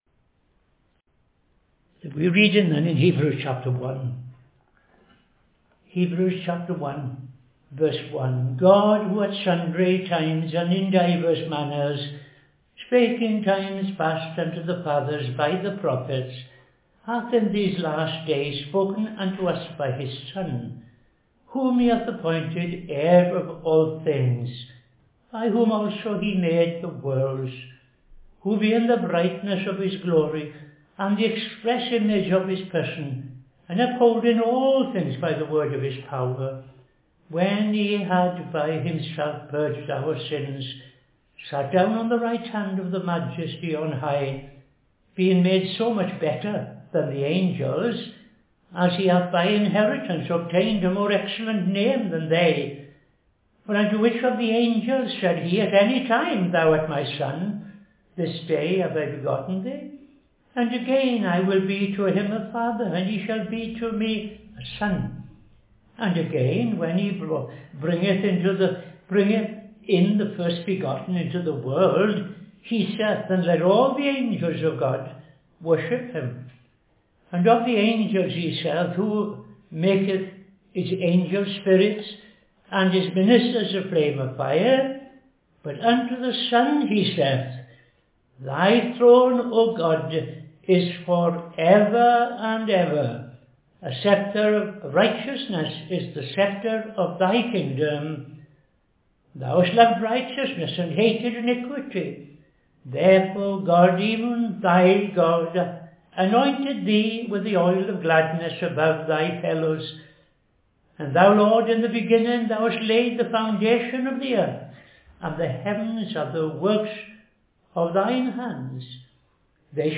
Reading Hebrews 1:1-14; II Timothy 4:16-18